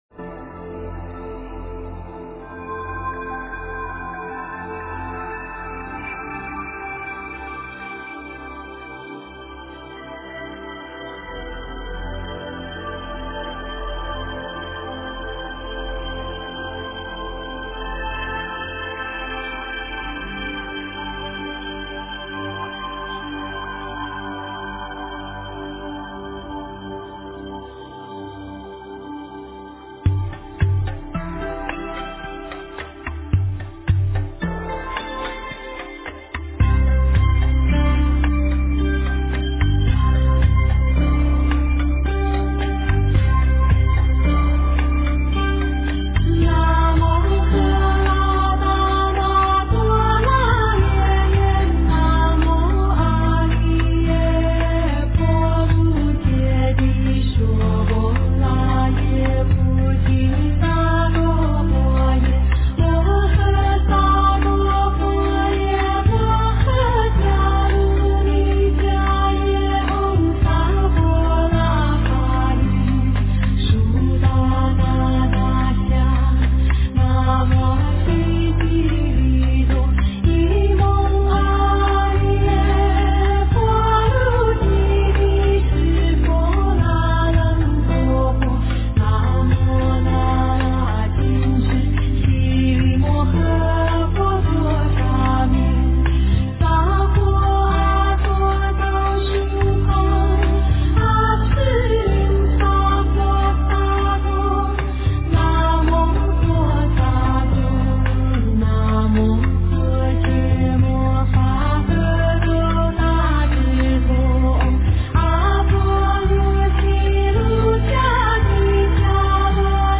古典风格